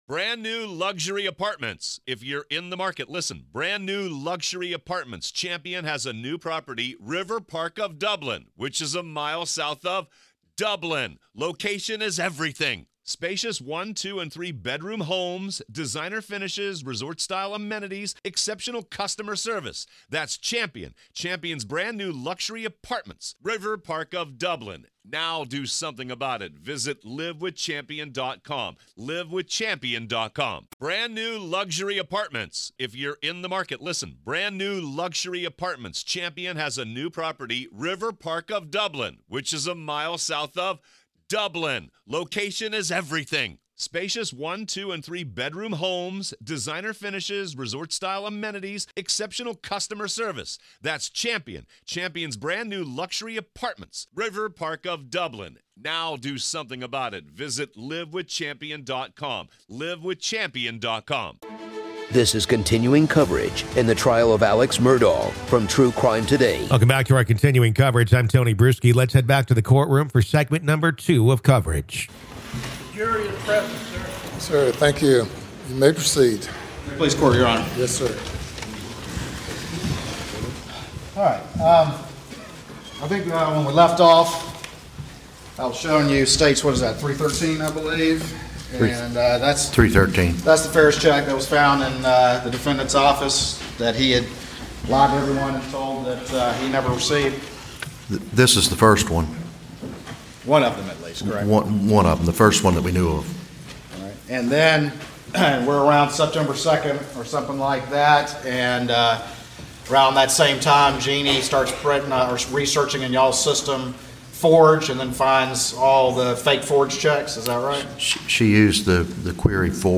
The Trial Of Alex Murdaugh | FULL TRIAL COVERAGE Day 20 - Part 2